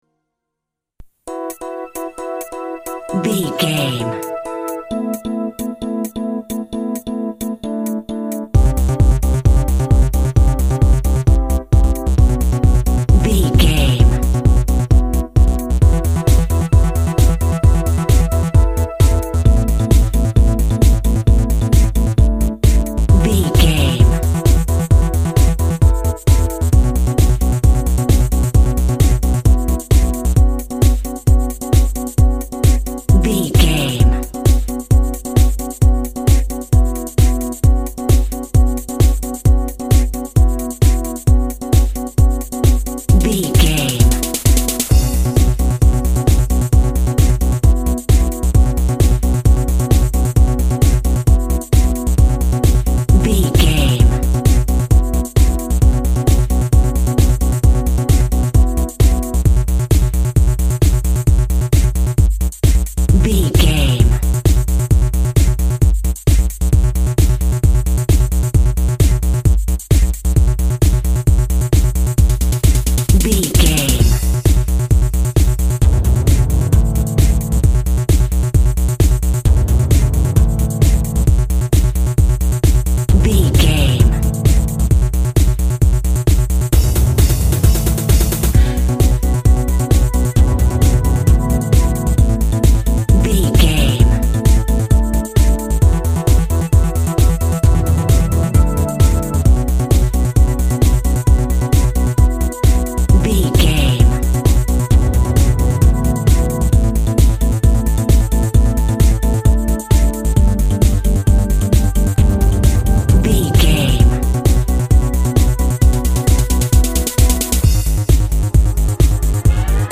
Weather Electronic Music.
Ionian/Major
groovy
uplifting
futuristic
energetic
cheerful/happy
synthesiser
drum machine
techno
trance
synth lead
synth bass
Synth Pads